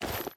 Equip_chain3.ogg.mp3